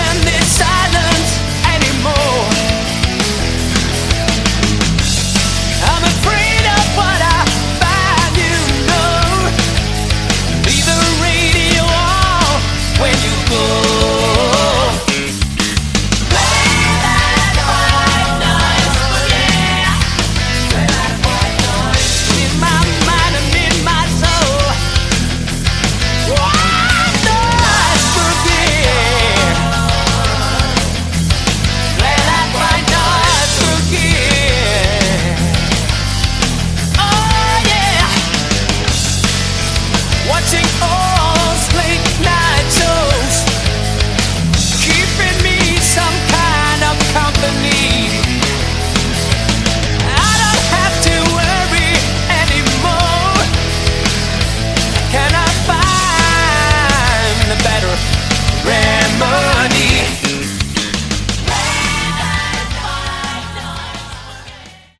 Category: Melodic Hard Rock
Vocals
Guitars
Bass
Drums
Keyboards